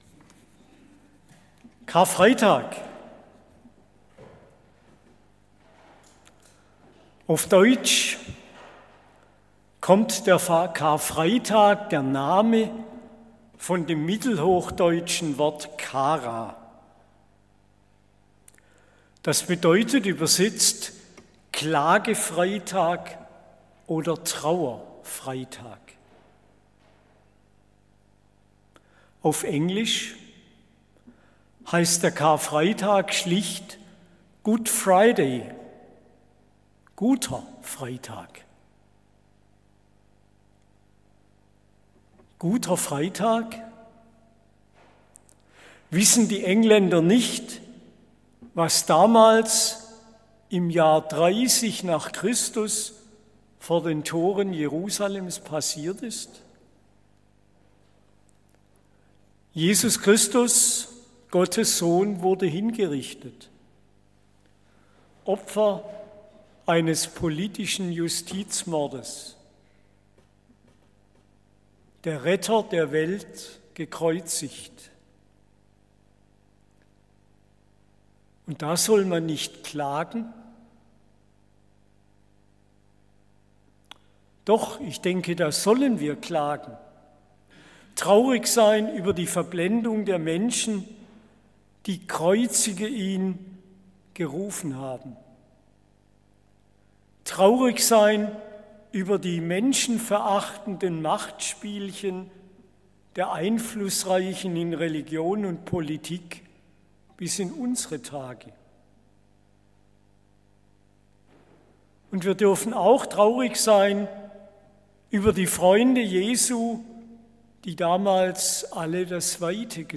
Die Predigten aus den Gottesdiensten der letzten drei Monate können als MP3-Datei heruntergeladen und nachgehört werden.